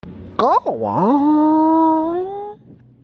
kiwaaa Meme Sound Effect
Category: Reactions Soundboard